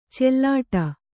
♪ cellāṭa